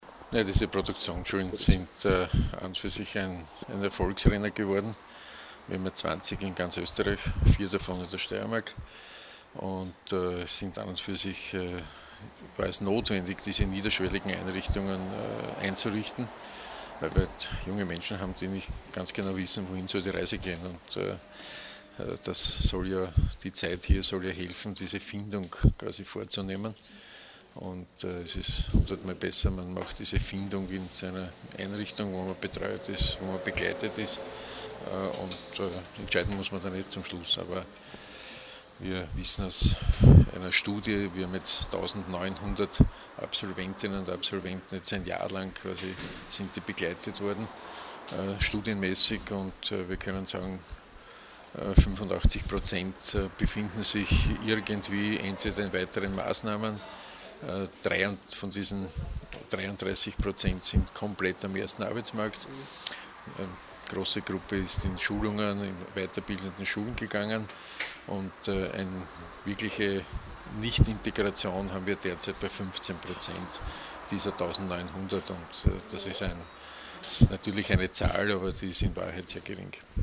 Zwei Jahre steirische Produktionsschulen - O-Töne
Bundesminister Rudolf Hundstorfer: